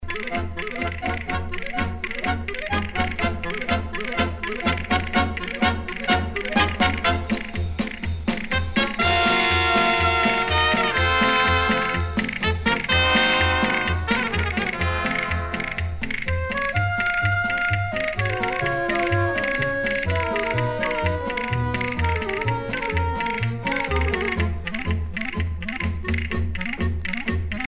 danse : paso-doble
Pièce musicale éditée